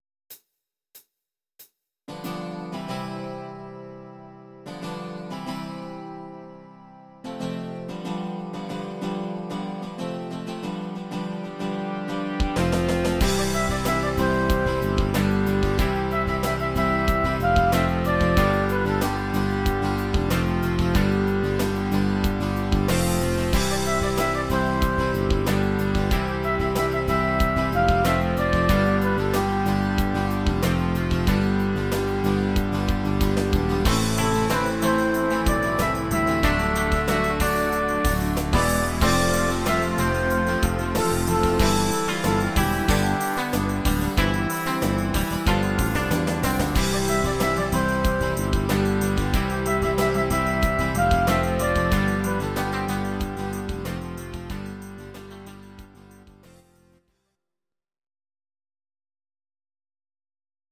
Audio Recordings based on Midi-files
Pop, Rock, 1990s